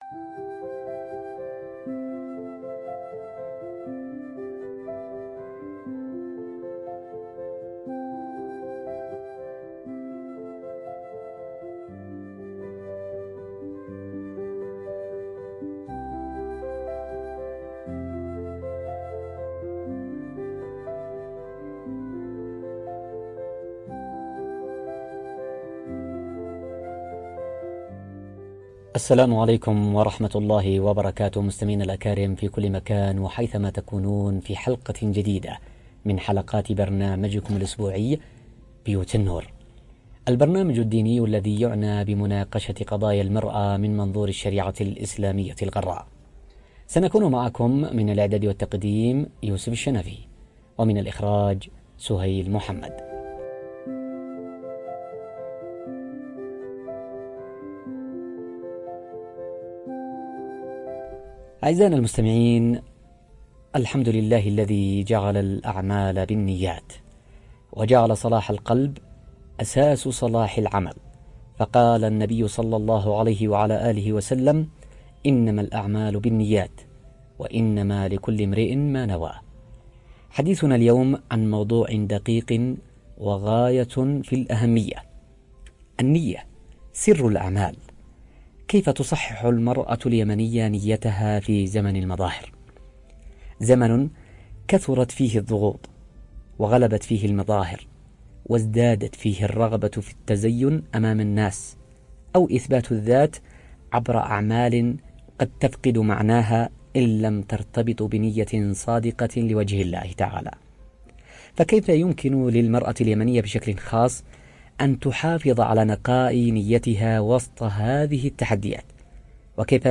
عبر أثير إذاعة رمز